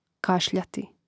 kàšljati kašljati